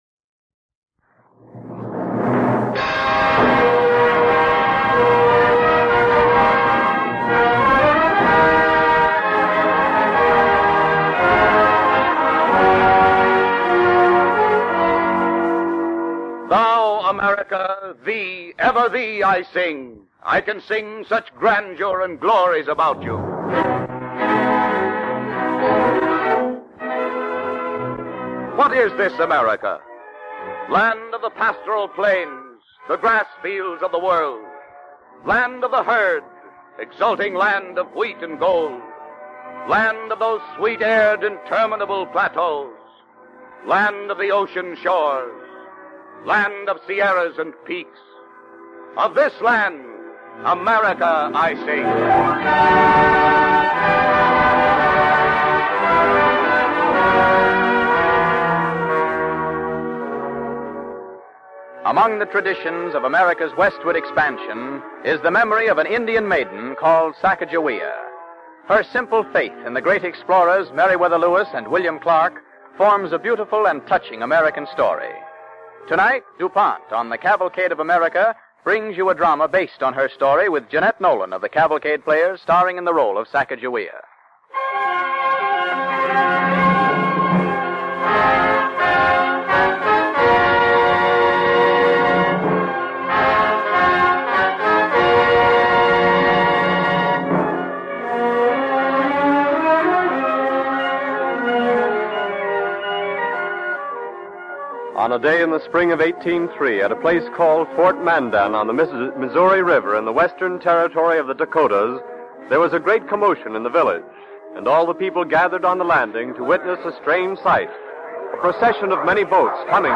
Listen to and download the Cavalcade of America Radio Program